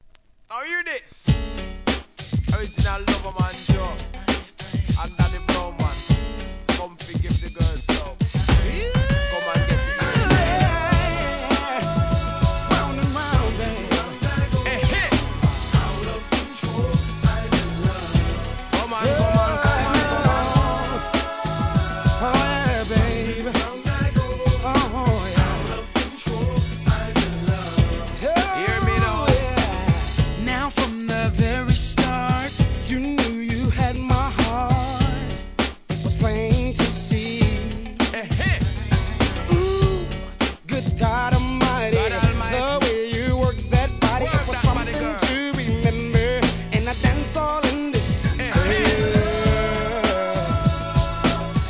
HIP HOP/R&B
1993年、NEW JACK SWINGトラックにラガMCの絡みが王道を行く傑作!!